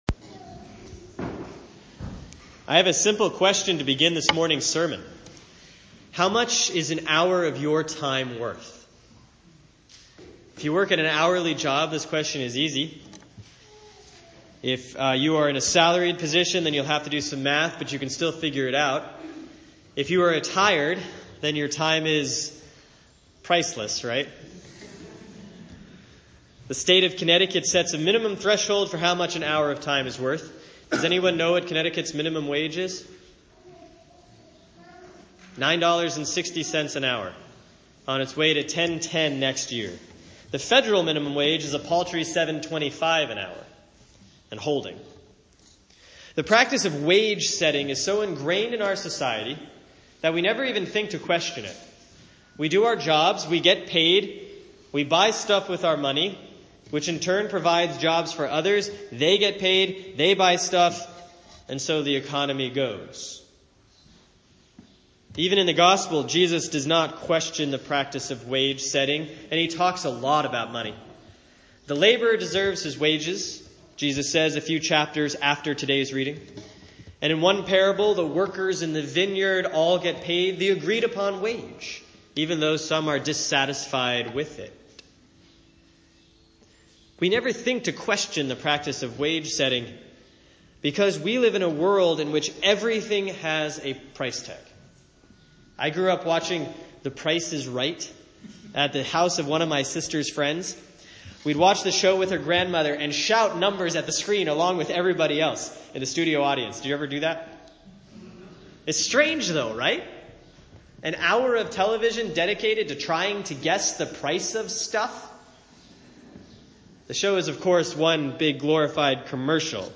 Sermon for Sunday, May 29, 2016 || Proper 4C || Luke 7:1-10